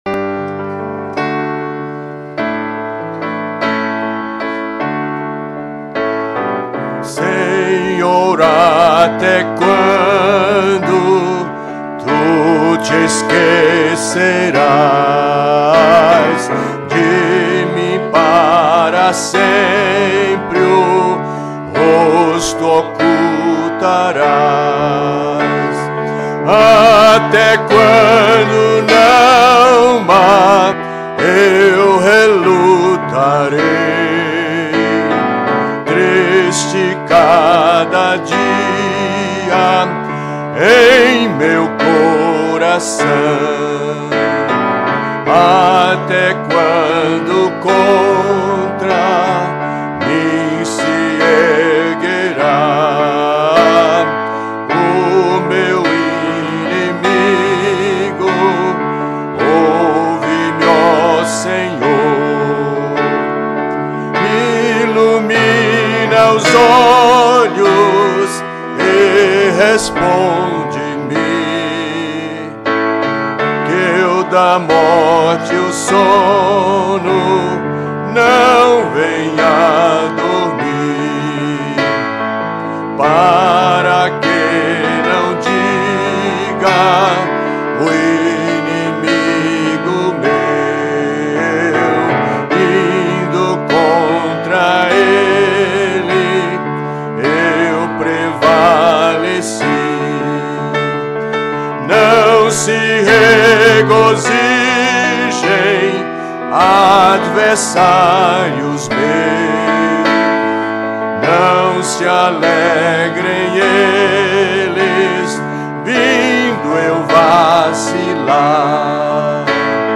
salmo_13B_cantado.mp3